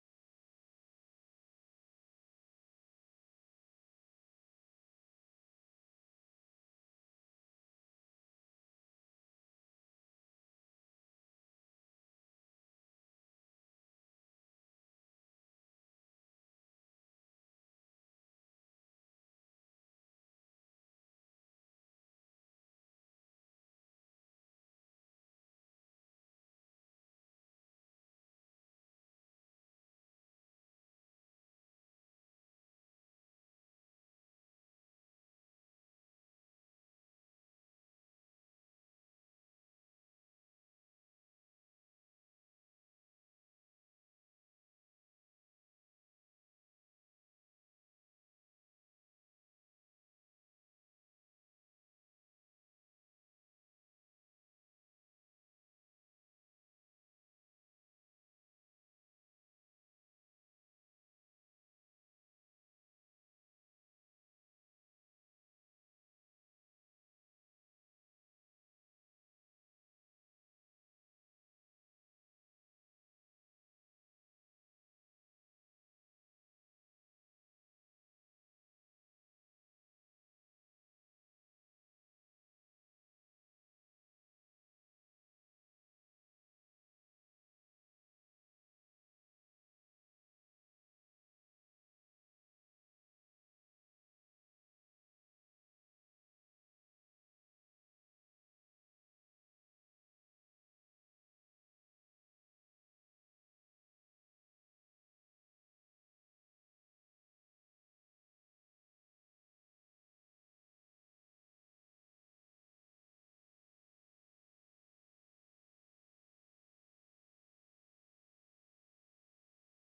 December 22 2024 Praise and Worship at FWC